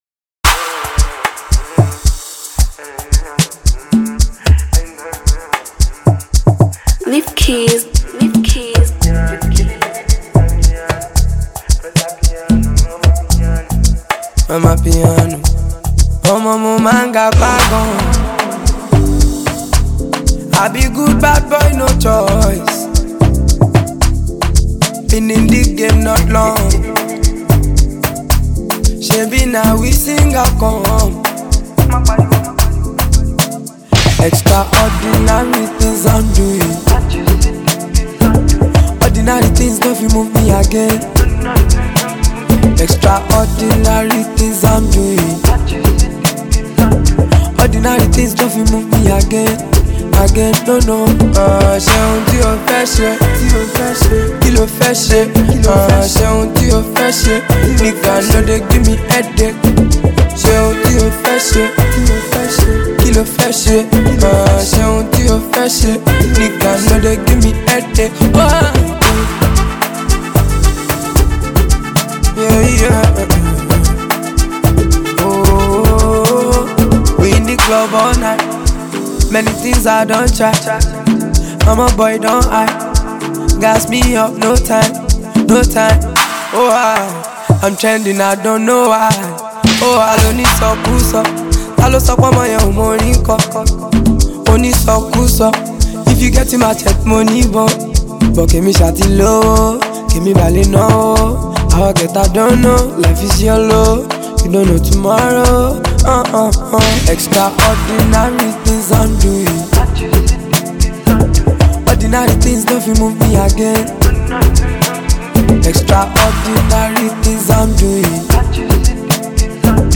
a perfect blend of Afrobeat and the Amapiano sound